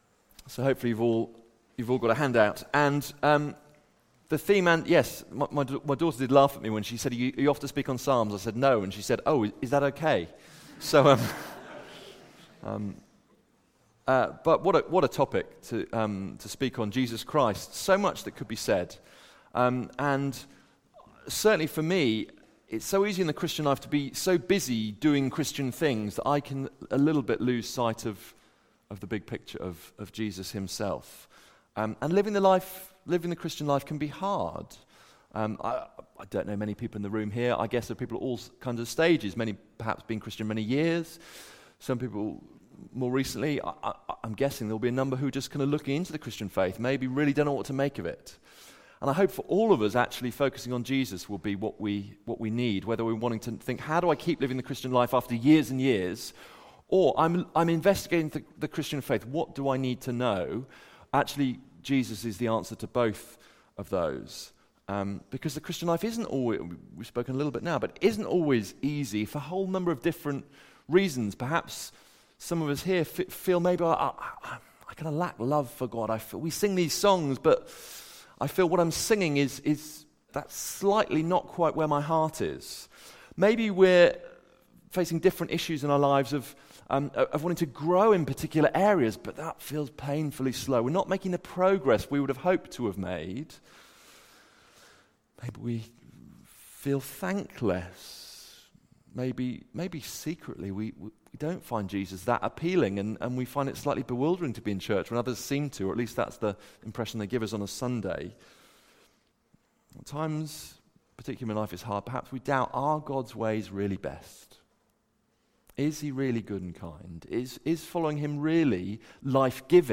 Preaching
Recorded at Woodstock Road Baptist Church on 29 June 2019.